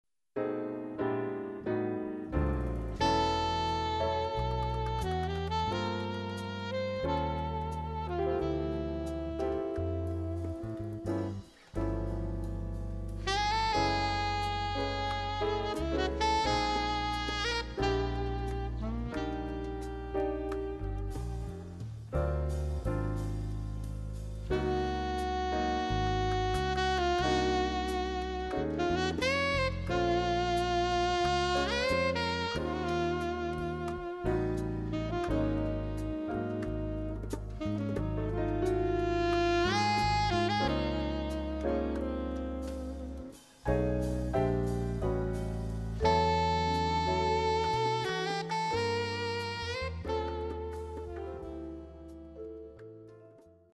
Latin jazz
vibes alto sax
Category: combo Style: bolero